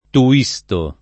vai all'elenco alfabetico delle voci ingrandisci il carattere 100% rimpicciolisci il carattere stampa invia tramite posta elettronica codividi su Facebook Tuisto [ tu- &S to ] o Tuistone [ tui S t 1 ne ] pers. m. mit.